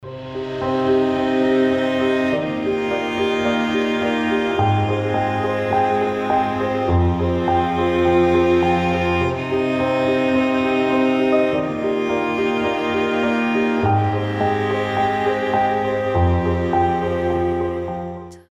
• Качество: 320, Stereo
красивые
грустные
спокойные
без слов
скрипка
пианино
нежные